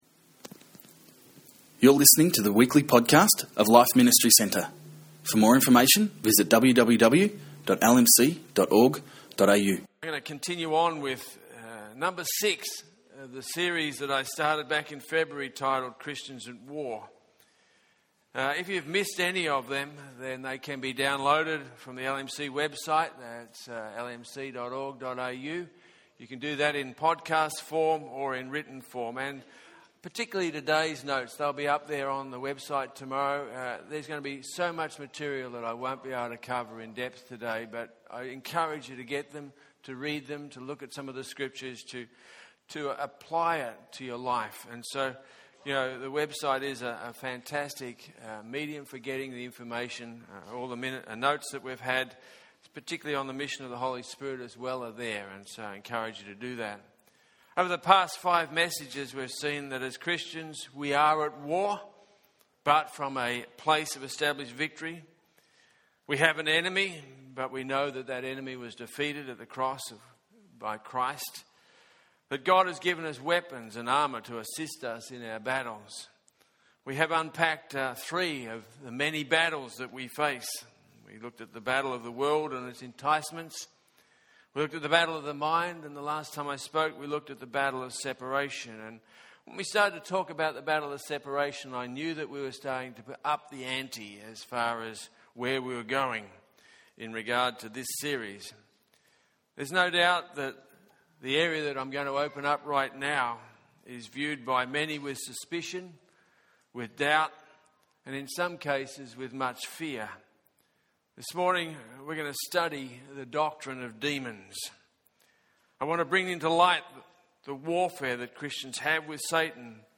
In this message on Christians At War